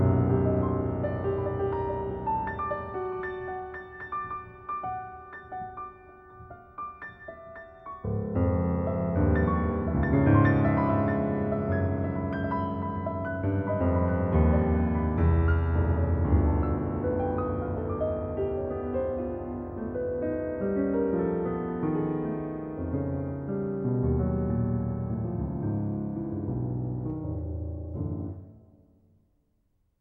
"templateExpression" => "Musique de chambre"